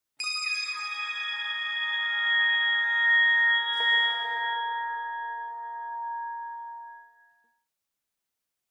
High Strings
Harmonic Plucks Harmonic Pad - Short Very High Pad With Some Pizzicatos Version 3